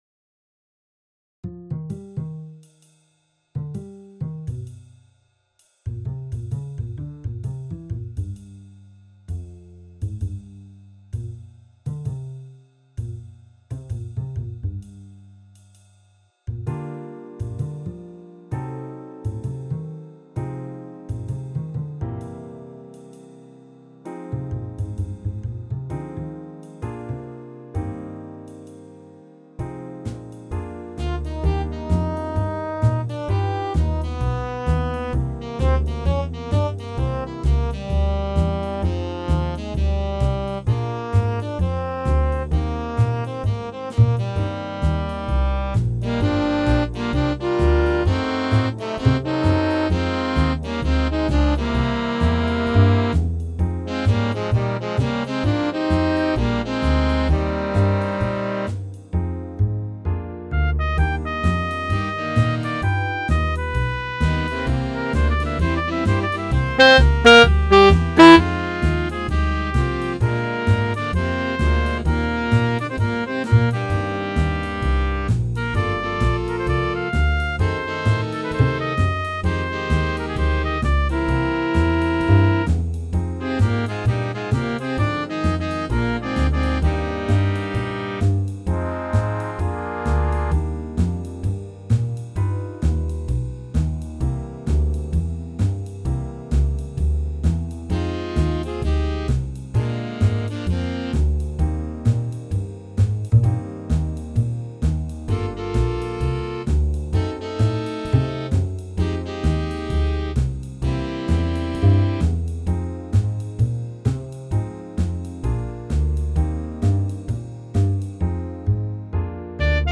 Big Band
All audio files are computer-generated.
A swing tune (tempo = 130) featuring a 3/4 on 4/4 section (D), a 5/4 section (F), a written bass solo and tenor sax and trombone improvised solos. Piano requires comping.